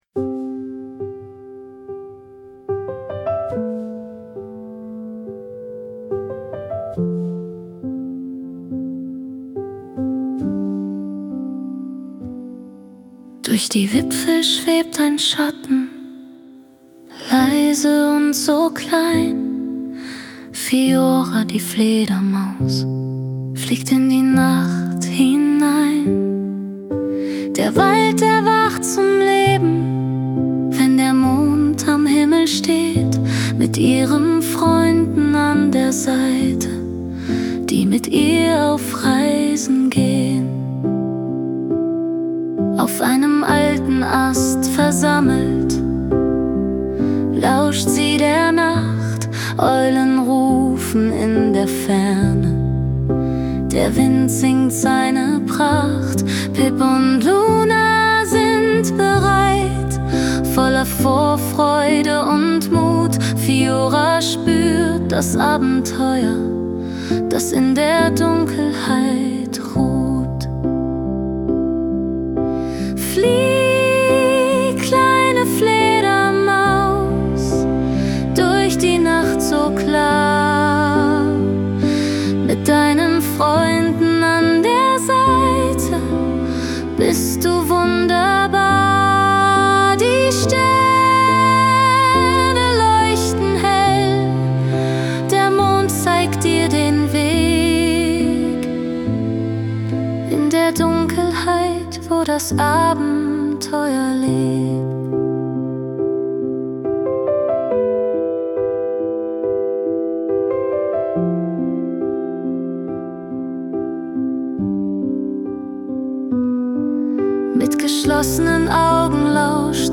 Das Schlaflied zur Geschichte